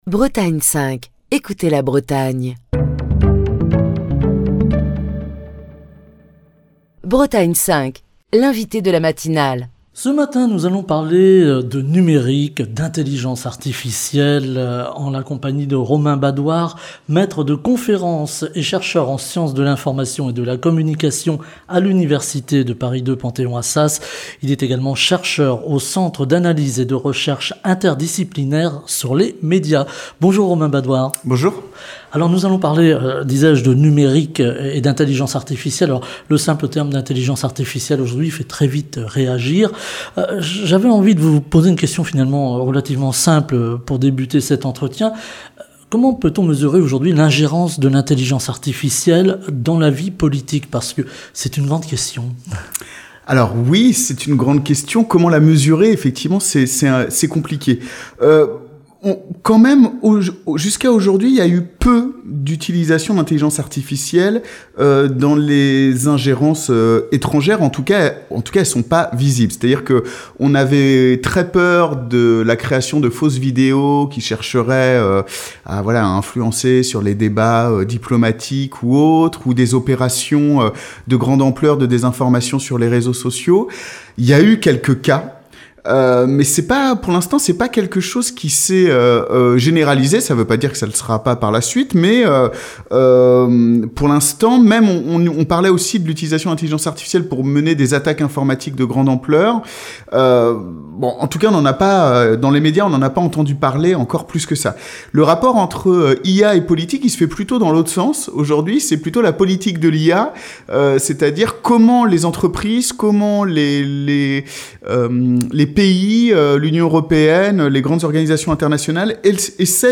Émission du 10 octobre 2025.